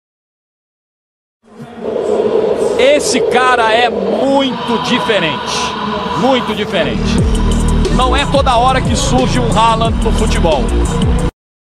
Narrador. 60 просмотров.